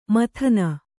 ♪ mathana